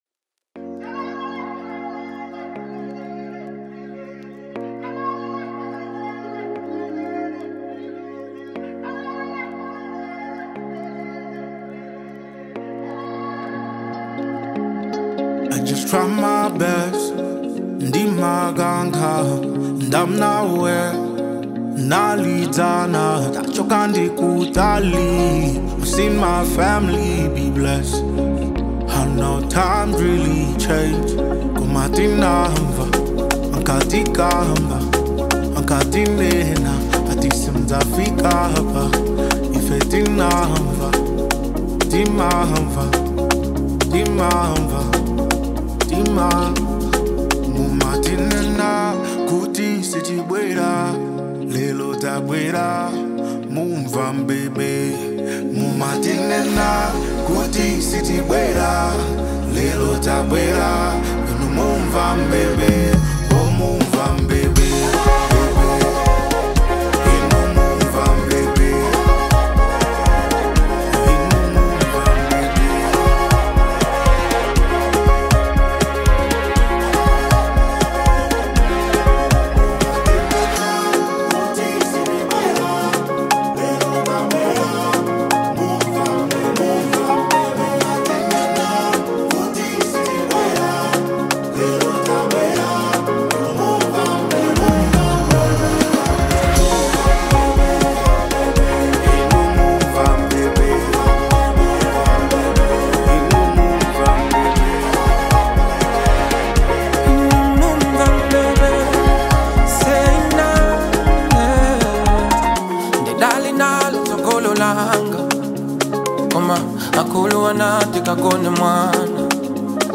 A Soulful Anthem
Set to a smooth, soulful instrumental with rich harmonies